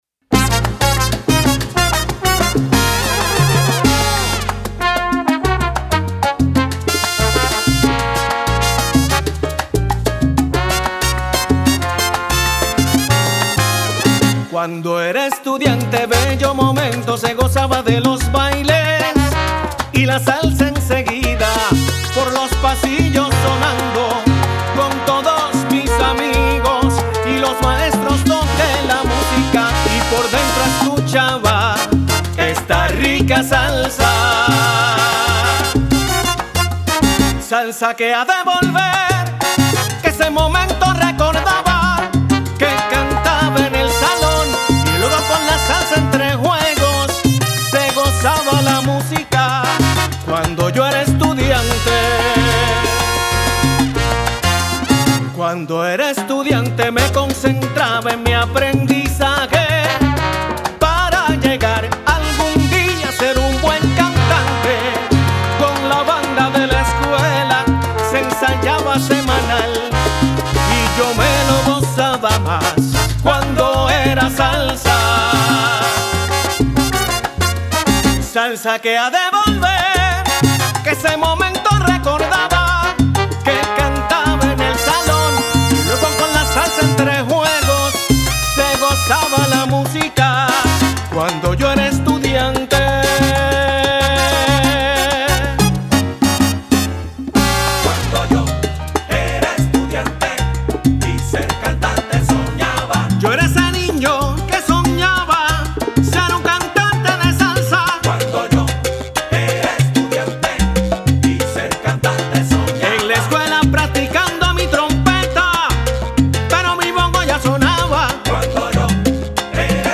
la pieza combina sensibilidad narrativa y riqueza sonora.